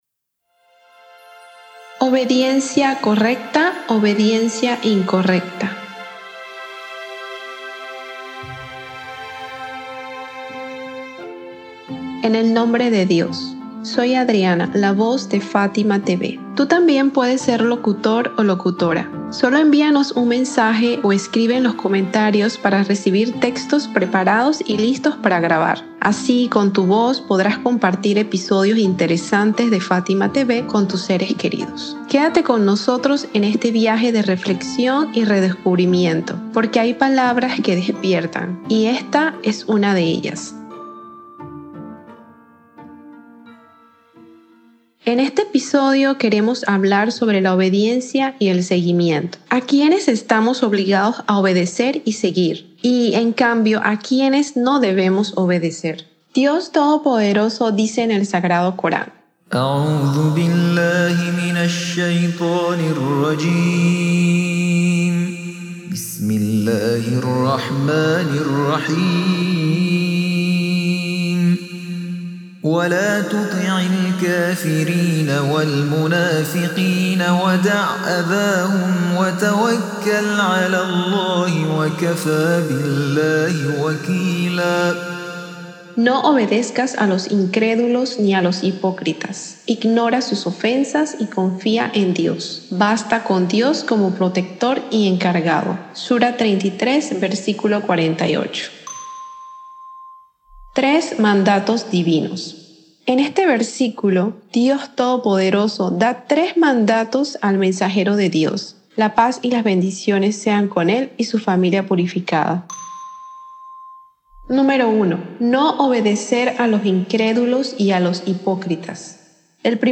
En este episodio reflexionamos sobre la obediencia: cuándo es correcta y necesaria, y cuándo se convierte en un error que nos aleja de la verdad. 🎙 Locutora: